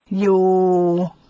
yuŭ